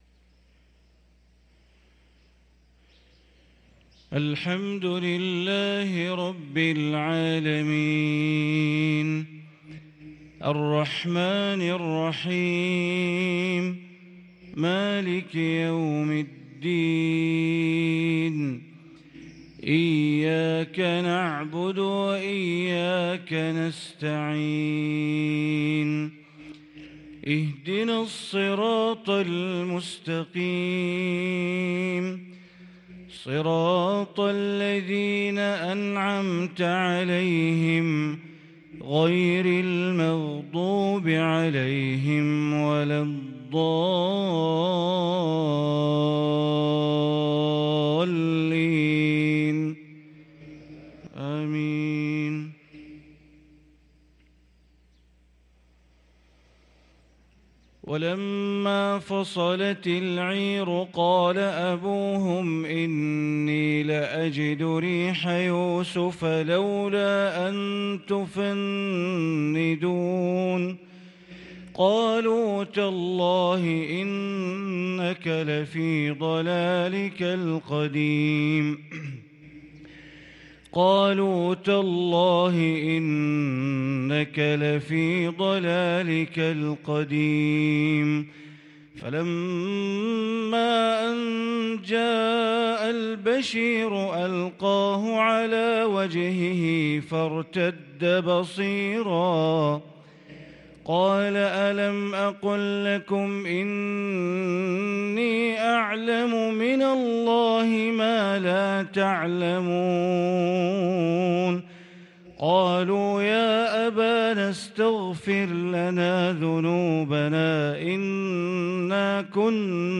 صلاة الفجر للقارئ بندر بليلة 17 ربيع الأول 1444 هـ
تِلَاوَات الْحَرَمَيْن .